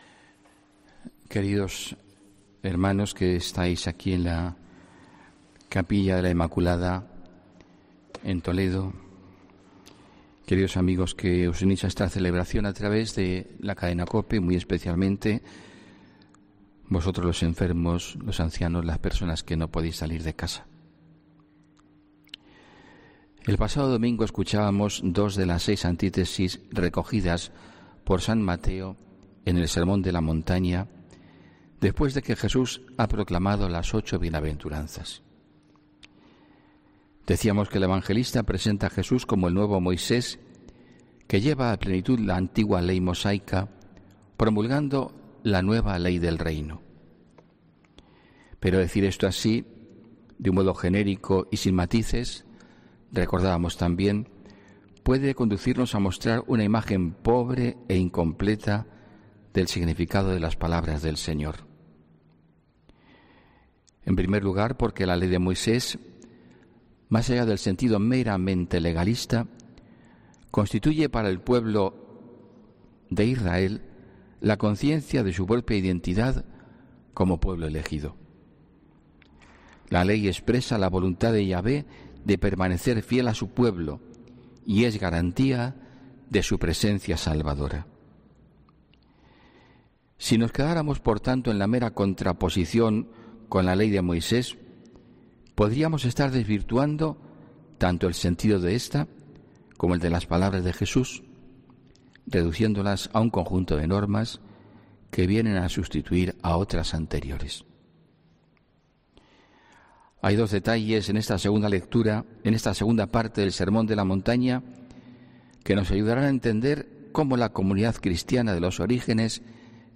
HOMILÍA 23 FEBRERO 2020